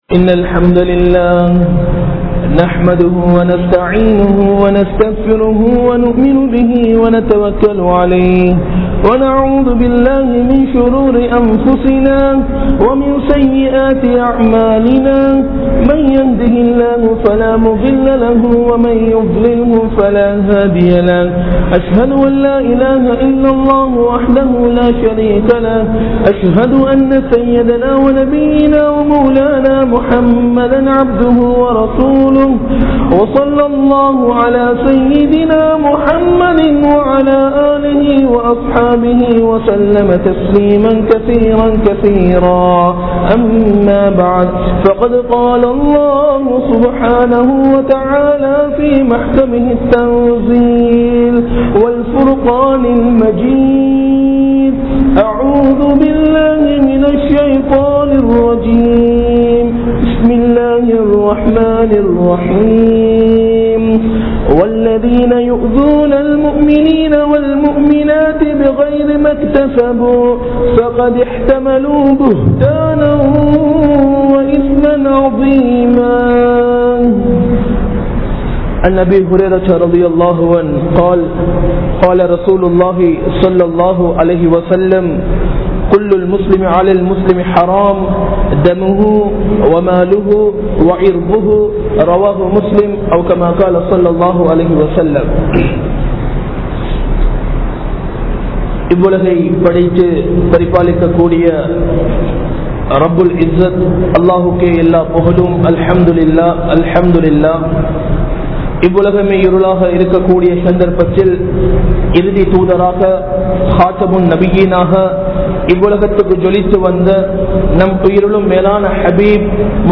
Nanmaihalai Alikkum 10 Paavangal (நன்மைகளை அழிக்கும் 10 பாவங்கள்) | Audio Bayans | All Ceylon Muslim Youth Community | Addalaichenai
Majma Ul Khairah Jumua Masjith (Nimal Road)